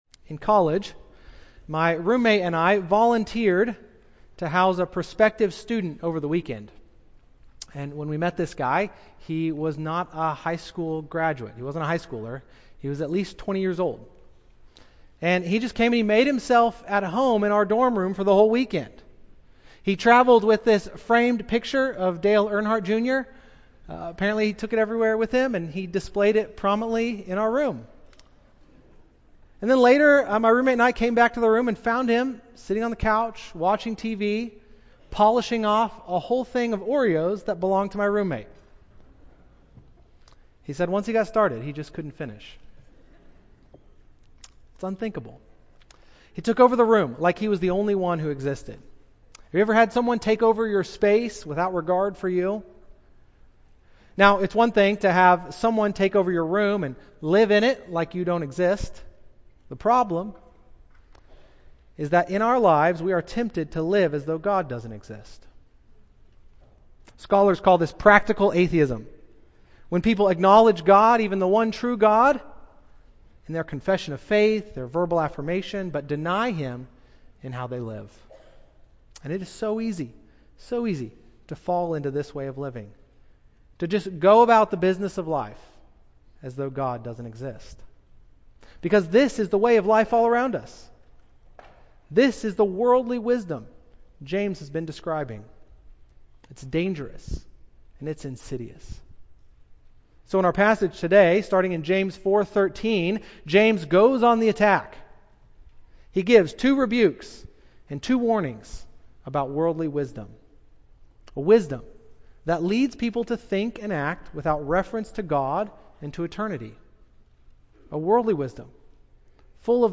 A sermon on James 4:13-5:6.